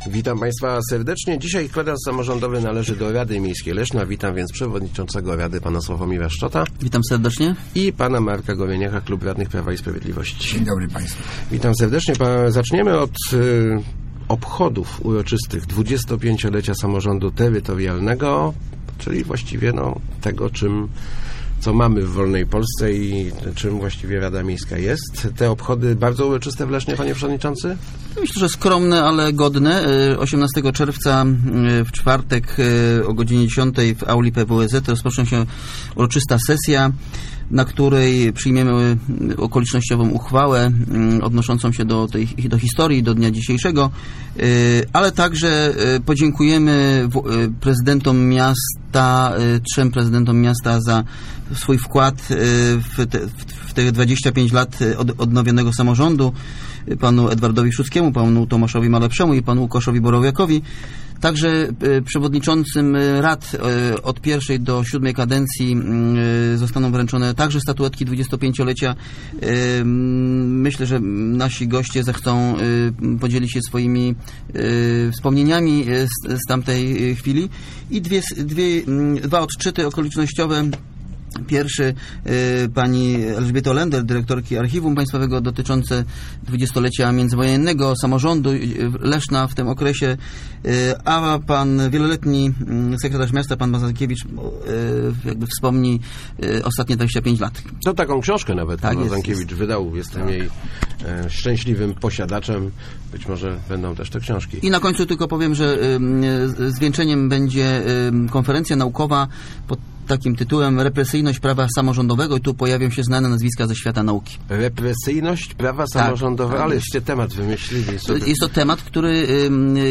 Gośćmi Kwadransa byli Sławomir Szczot, przewodniczący RML i Marek Goryniak, przewodniczący Komisji Rewizyjnej.